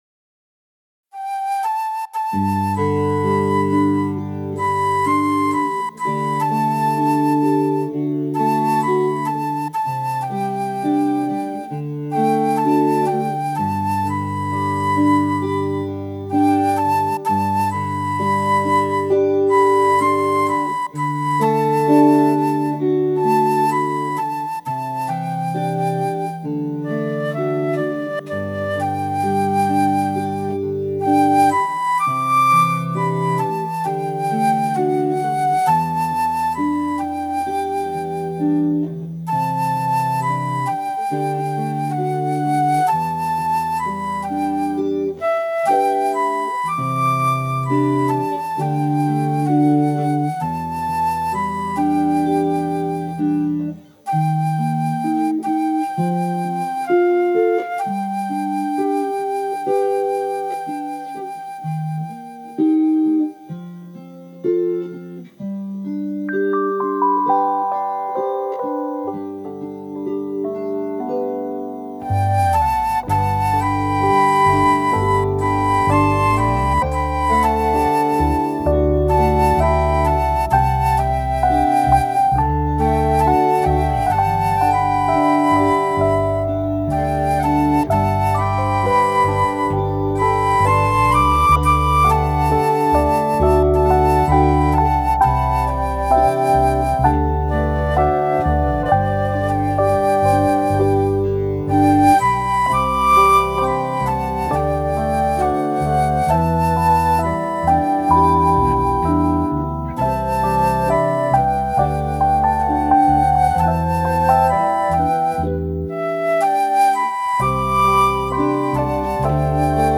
曲は、多くの人にこれが私本来の持ち味だと思われている(ようです)、リズムなしのゆったりした生っぽい曲です。